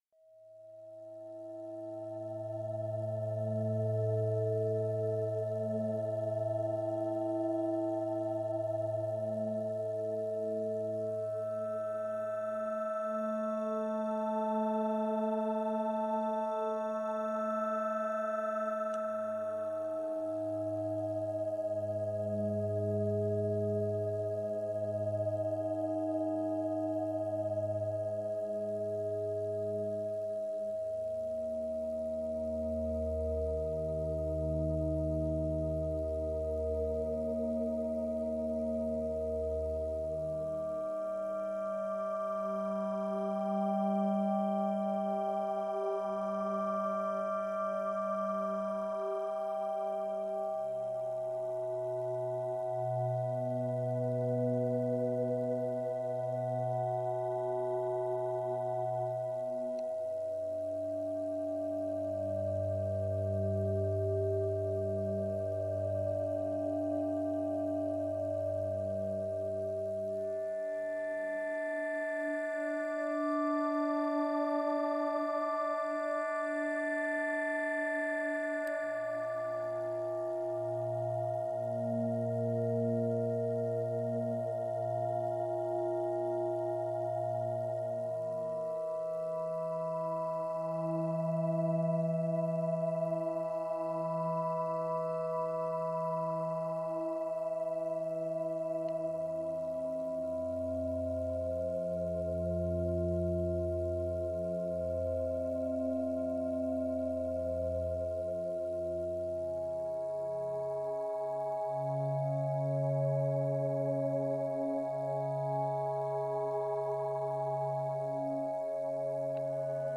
FA – 639 Hz – Frecuencia para la conexión y las relaciones